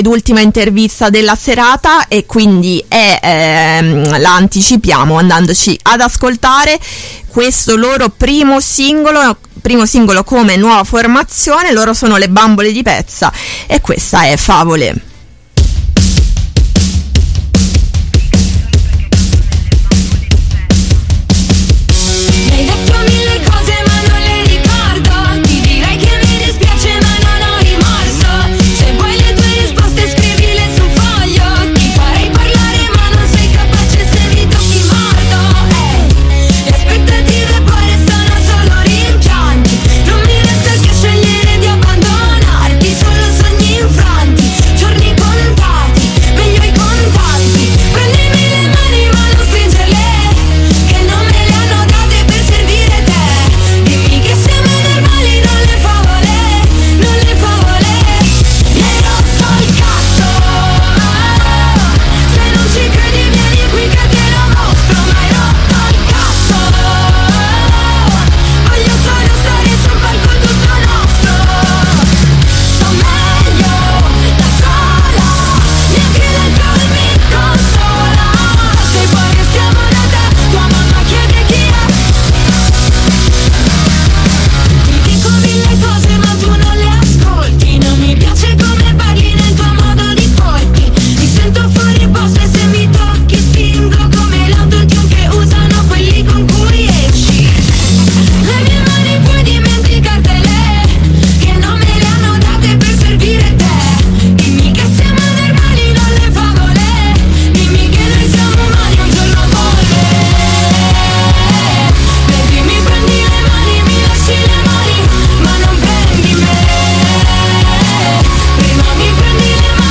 INTERVISTA ALLE BAMBOLE DI PEZZA